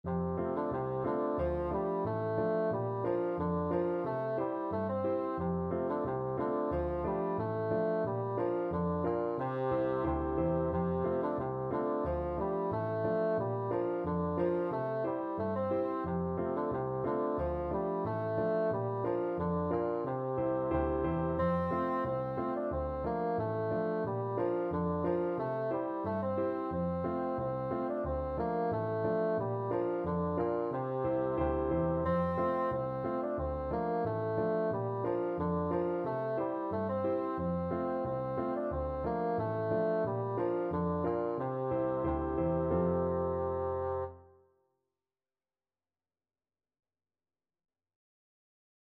4/4 (View more 4/4 Music)
Very fast = c.180
Scottish